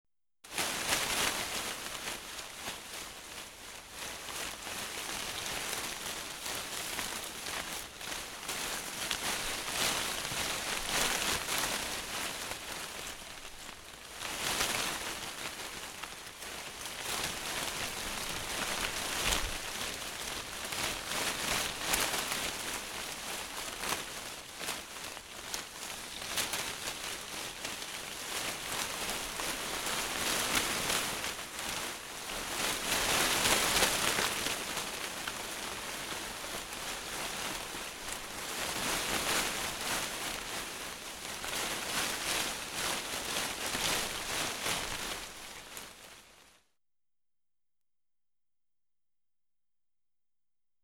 Scary Sounds - 42 - Leaves Rustling Type